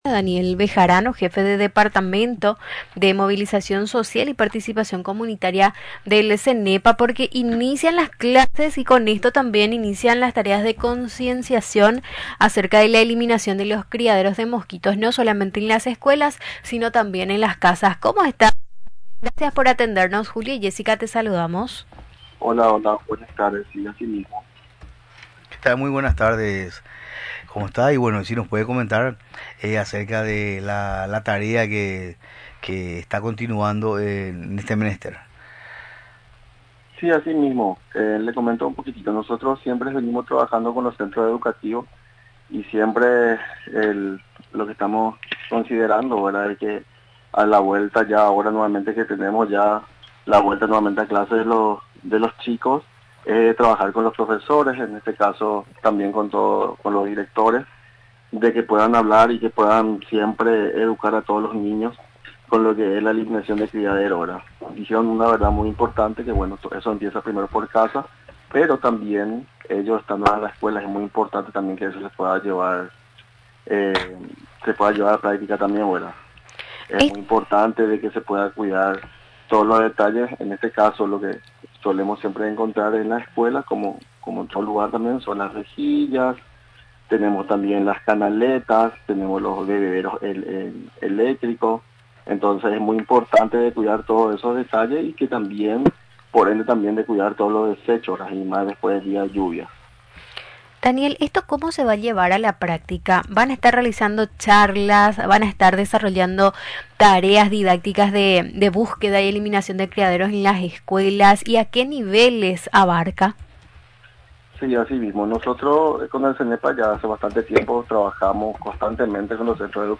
Explicó, durante la entrevista en Radio Nacional del Paraguay, que los funcionarios de la institución llegarán hasta las escuelas y colegios, a fin de brindar charlas de capacitación.